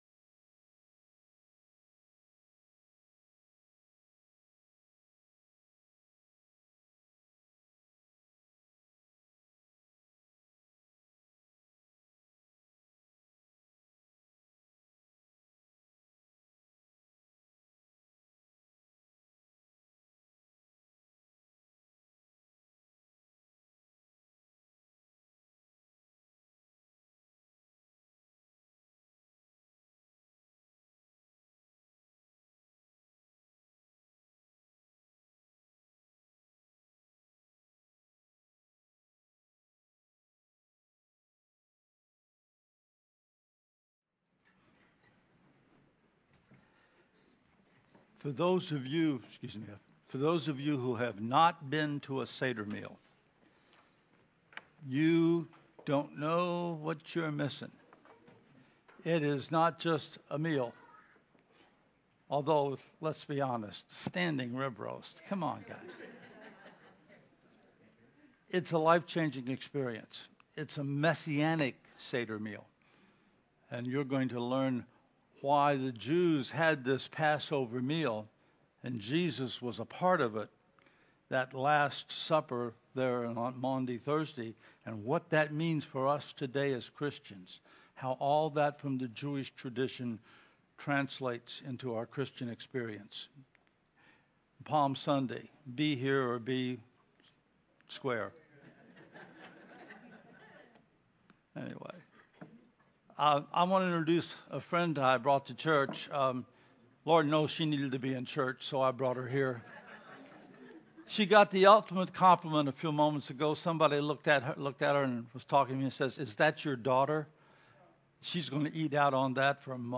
“Sacrifice” Sermon (03-20-22)